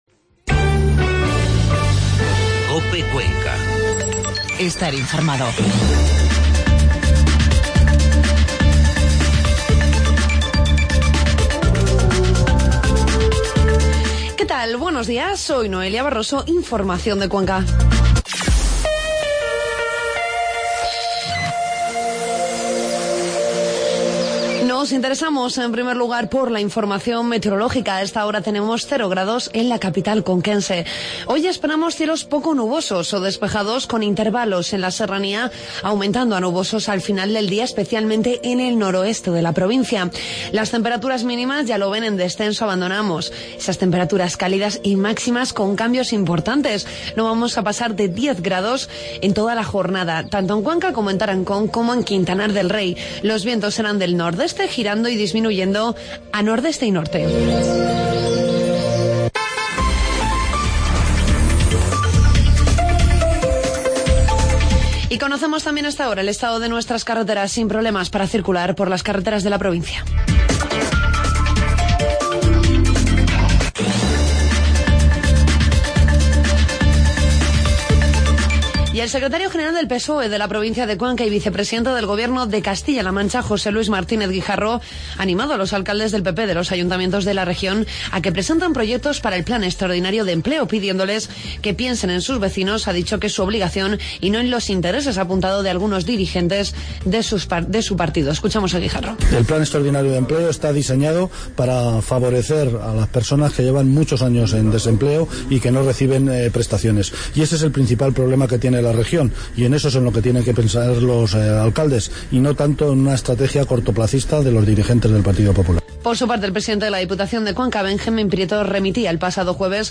Informativo matinal COPE Cuenca lunes 23 de noviembre